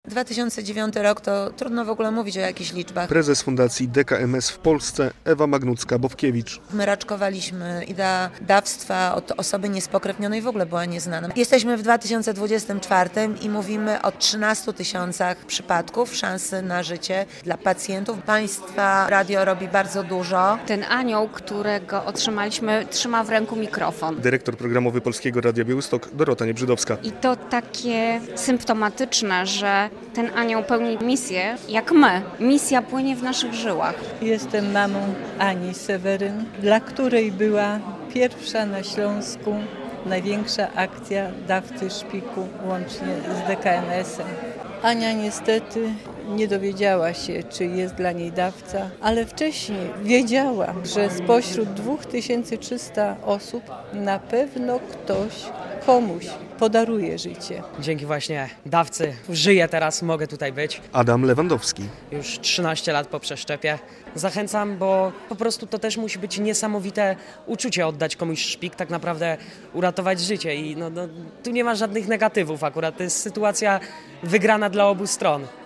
relacja
Okazją do podsumowań, podziękowań i wspomnień była uroczysta gala w Warszawie, podczas której przyznano Anioły Fundacji DKMS.